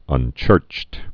(ŭn-chûrcht)